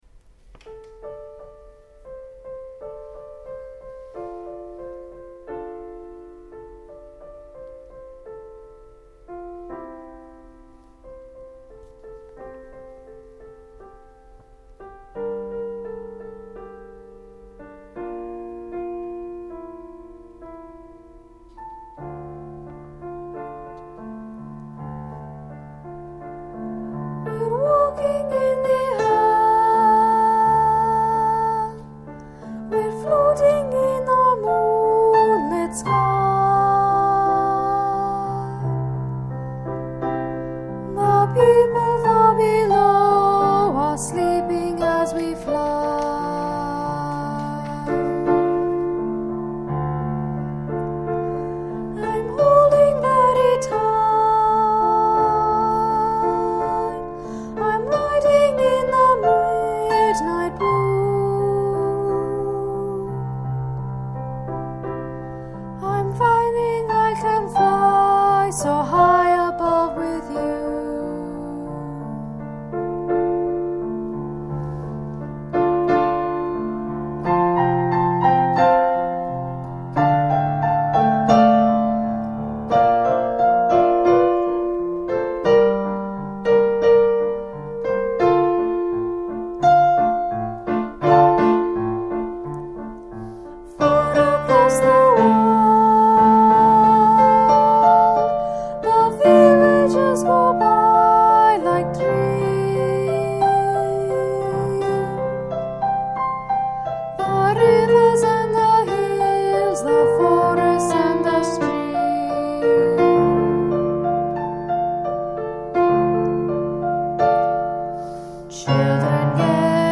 The 2006 Blogger Christmahanukwanzaakah Online Holiday Concert